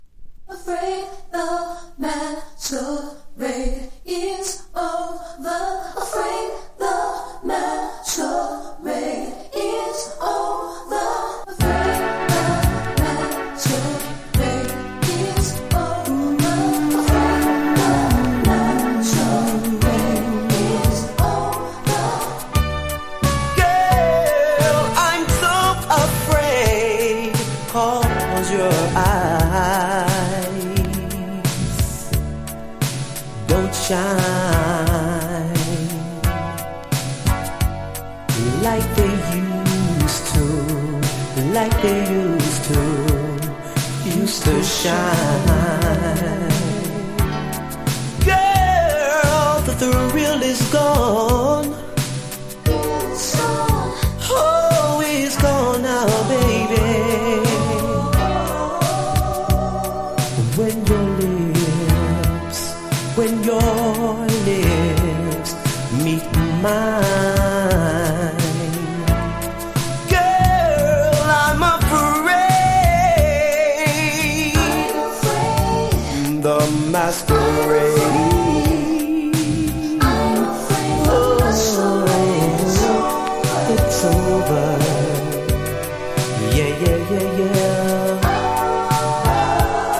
甘いブラック・コンテンポラリーナンバー！！